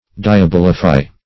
Search Result for " diabolify" : The Collaborative International Dictionary of English v.0.48: Diabolify \Di`a*bol"i*fy\, v. t. [L. diabolus devil + -fy.] To ascribed diabolical qualities to; to change into, or to represent as, a devil.